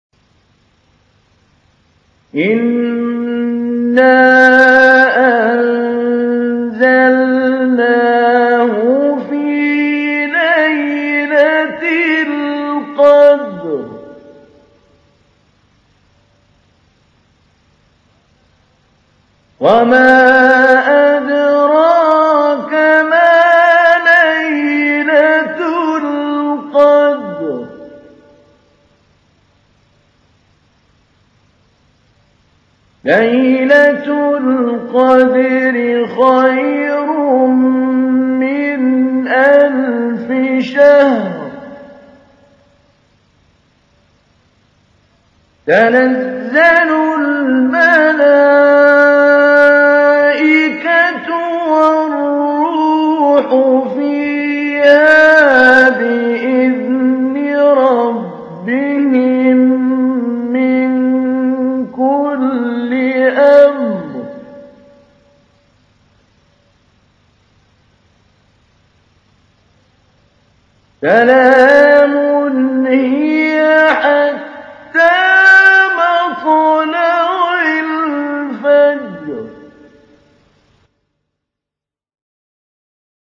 تحميل : 97. سورة القدر / القارئ محمود علي البنا / القرآن الكريم / موقع يا حسين